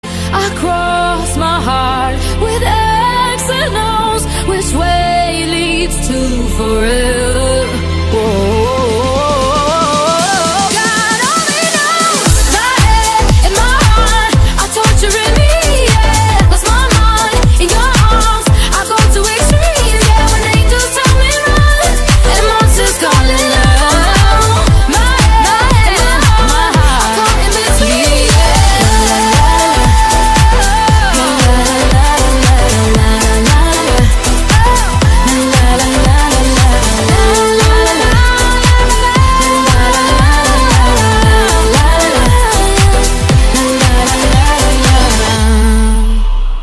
Kategorien POP